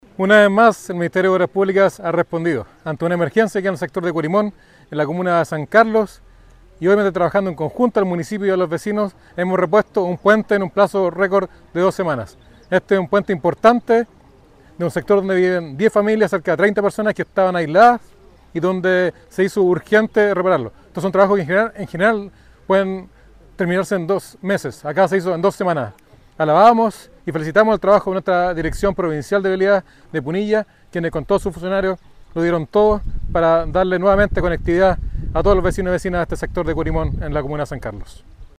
El seremi de Obras Públicas de Ñuble, Freddy Jelves, informó que la reconstrucción del puente permitió restablecer la conectividad para unas 10 familias y que la intervención se efectuó en coordinación con el municipio y los vecinos.
Freddy-Jelves-seremi-MOP-3.mp3